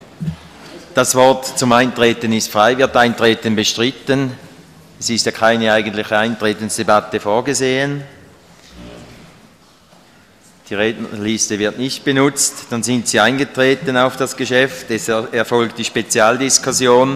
26.11.2012Wortmeldung
Session des Kantonsrates vom 26. bis 28. November 2012